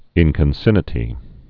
(ĭnkən-sĭnĭ-tē)